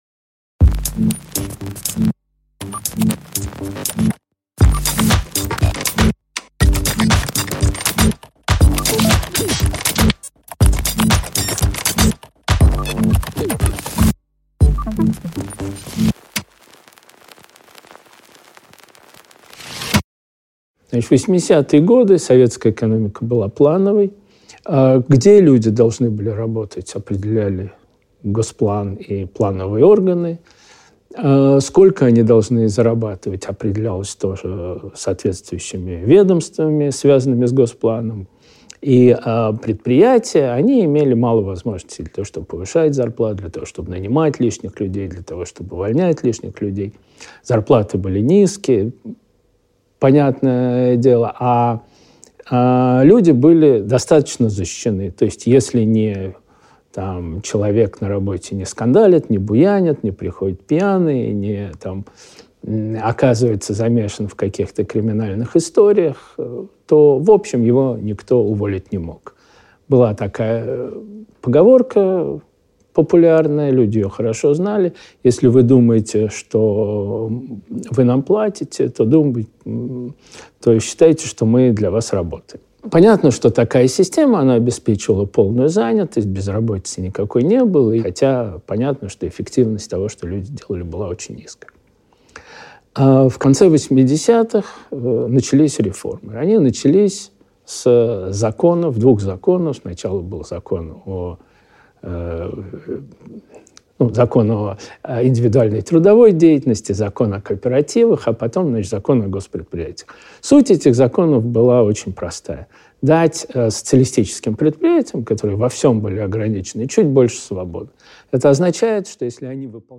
Аудиокнига Специфика постсоветского рынка труда | Библиотека аудиокниг